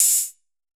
UHH_ElectroHatD_Hit-04.wav